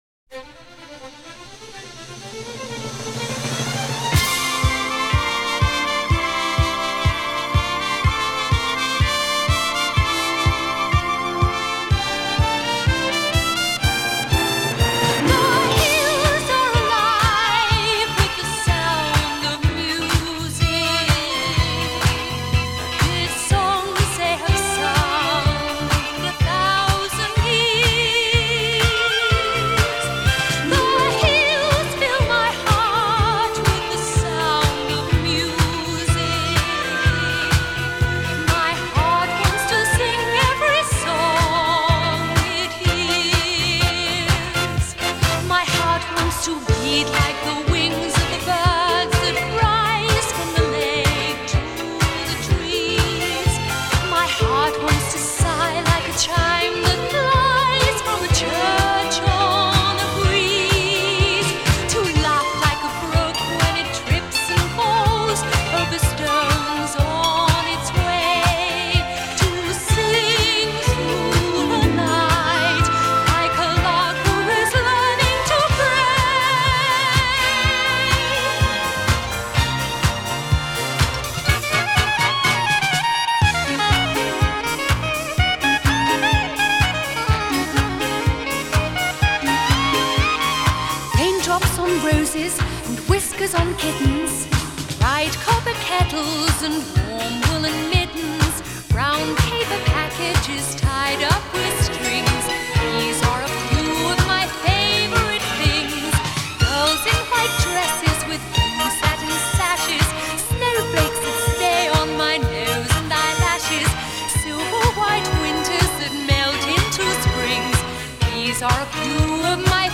Vinyl rip